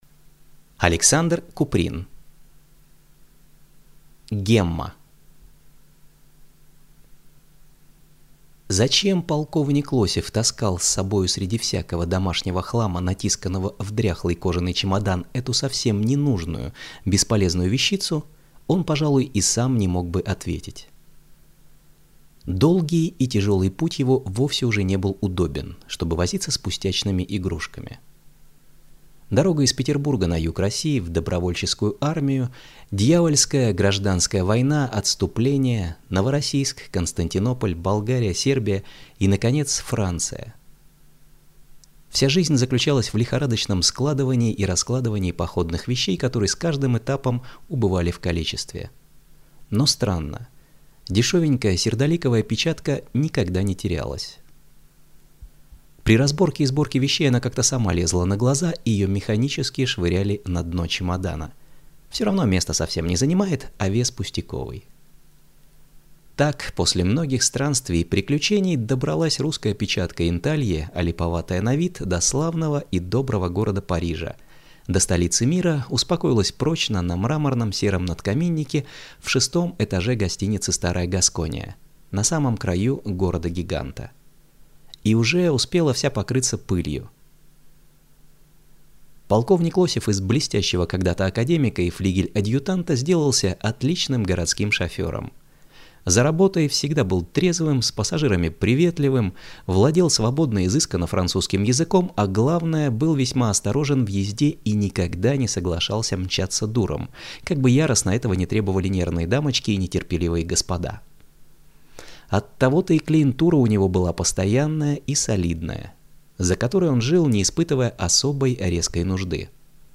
Аудиокнига Гемма | Библиотека аудиокниг